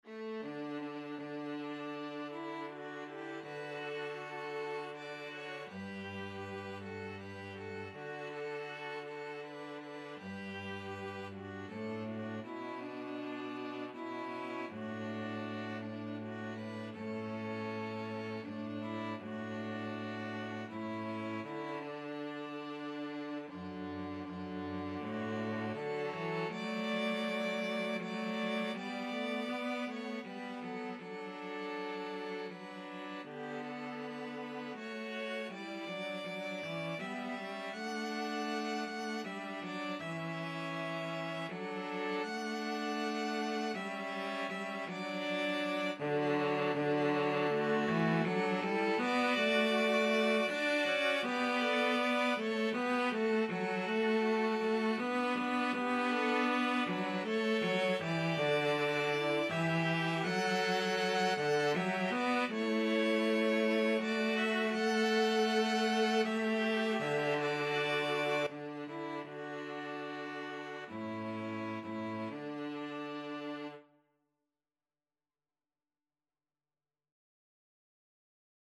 Free Sheet music for String trio
ViolinViolaCello
"Oh Shenandoah" (also called simply "Shenandoah", or "Across the Wide Missouri") is a traditional American folk song of uncertain origin, dating at least to the early 19th century.
G major (Sounding Pitch) (View more G major Music for String trio )
3/4 (View more 3/4 Music)
Andante